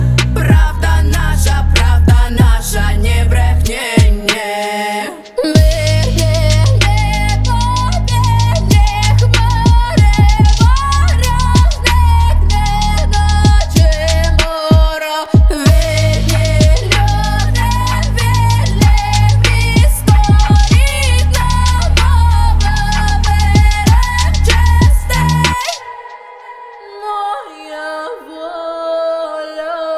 Жанр: Рэп и хип-хоп / Русские